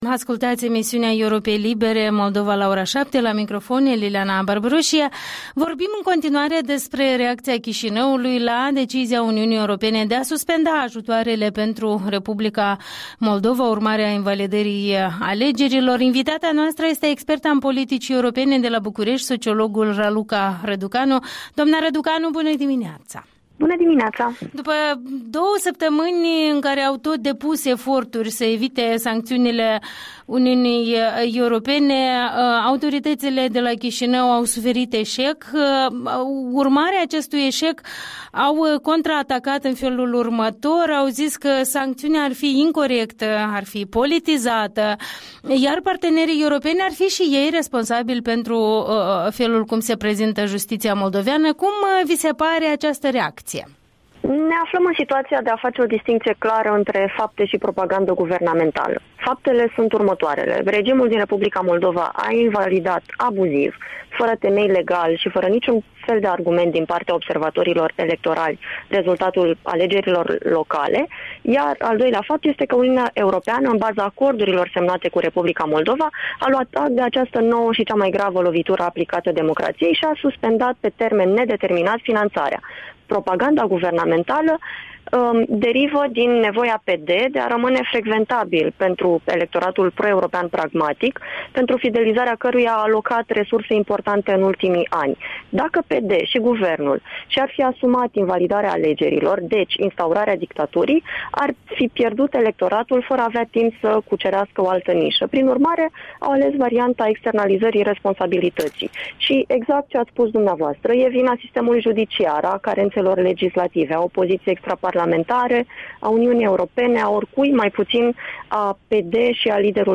Interviul dimineții cu experta în politici europene de la București despre sancțiunile UE la adresa R. Moldova.